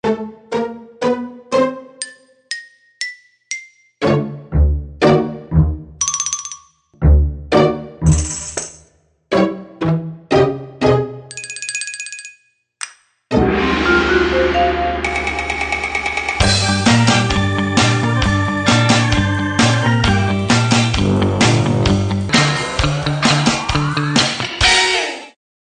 запись деревянных духовых на концерте
При записи состава вокал+ 2 акуст. гитары+бас+перкуссия+гобой на живом концерте в небольшом помещении возникает проблема с треком гобоя. Инструмент снимался динамическим микрофоном с фронта, со стороны клапанов, звук выводился на порталы т в мониторы.